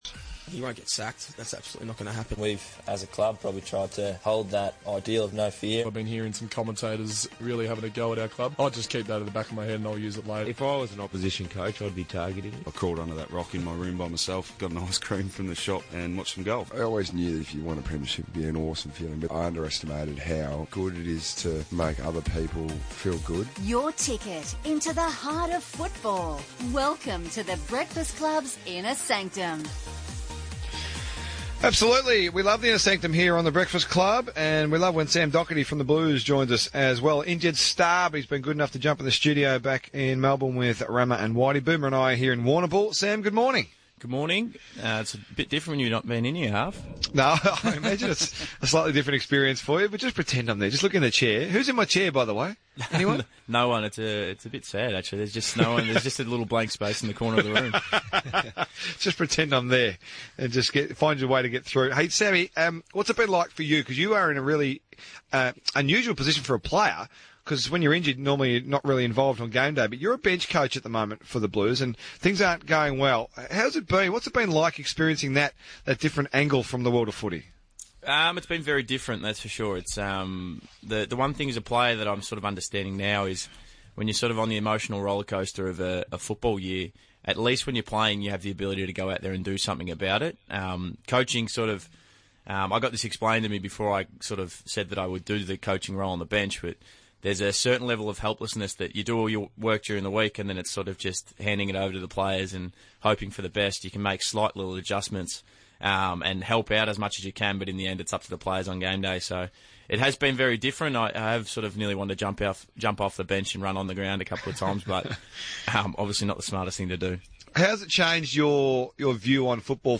Injured Blues star Sam Docherty joins RSN Breakfast's Inner Sanctum for an in-depth chat.